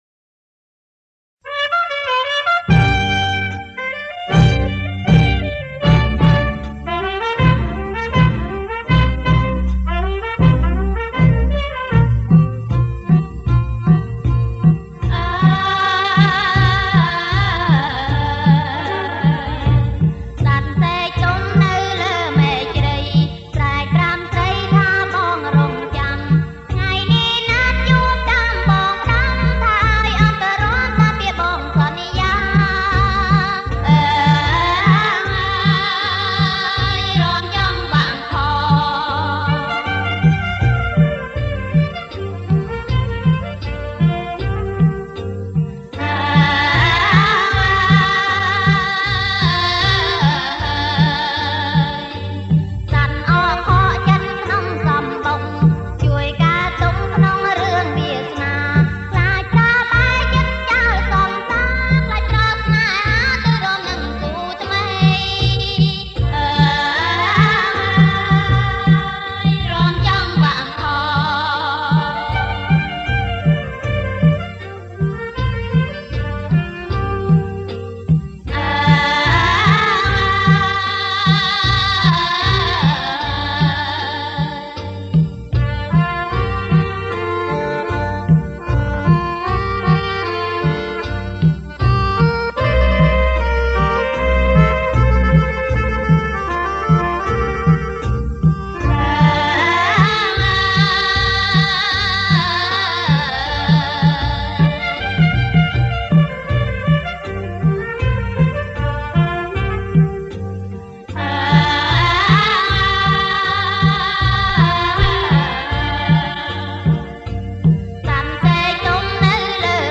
• ប្រគំជាចង្វាក់ Roamken